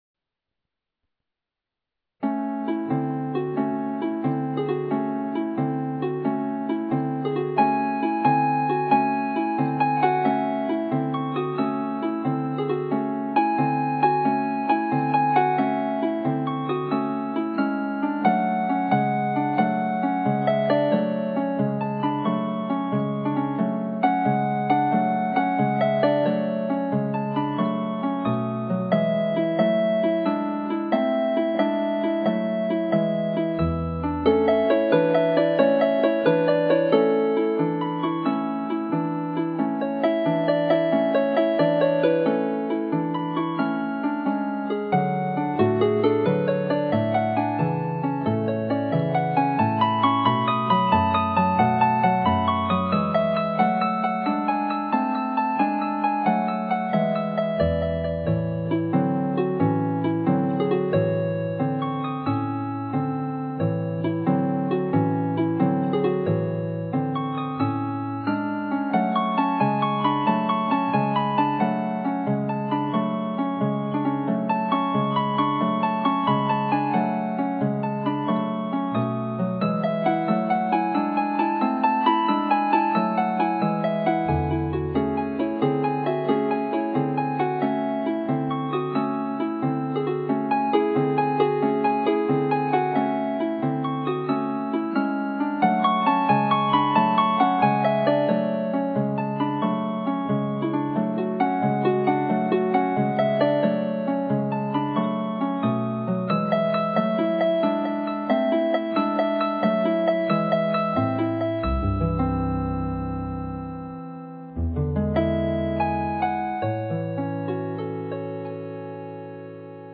A ballade for Celtic (folk) harp, composed in 2011.